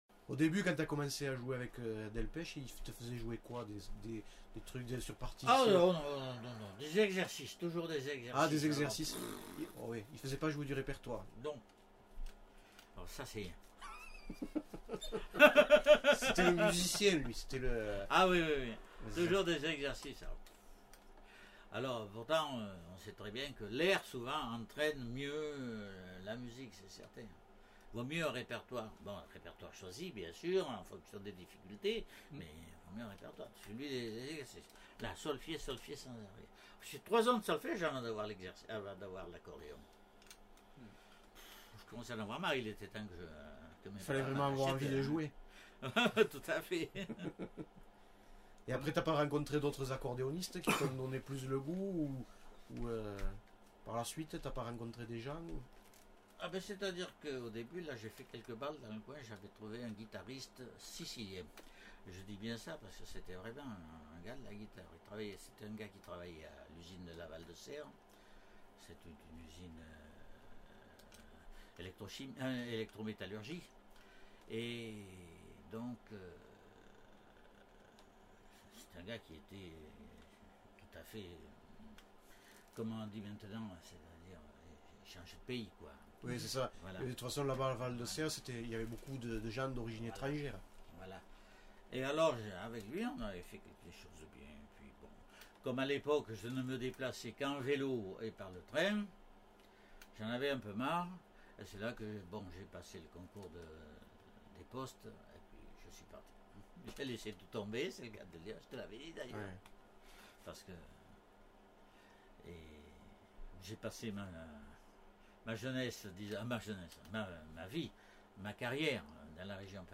Aire culturelle : Quercy
Genre : récit de vie